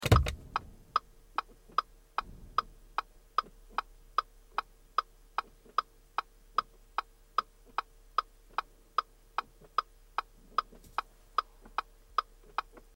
Звуки Audi A4
Звук работы поворотников этого автомобиля